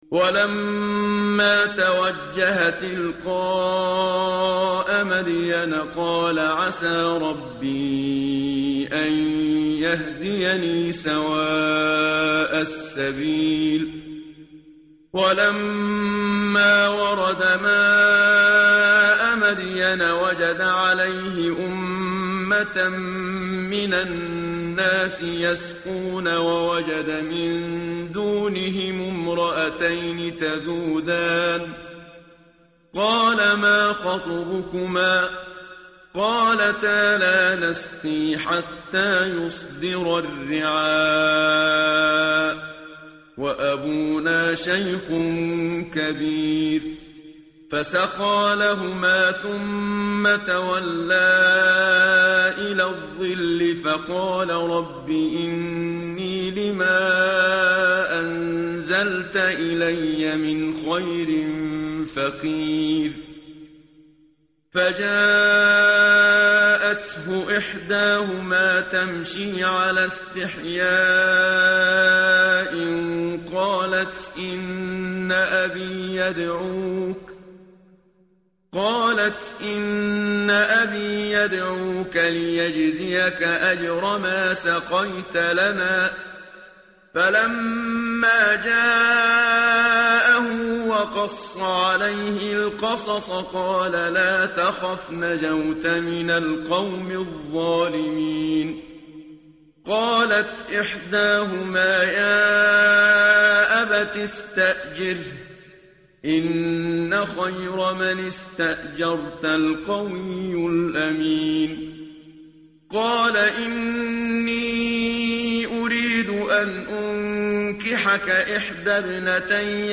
tartil_menshavi_page_388.mp3